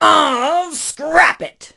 pam_die_vo_02.ogg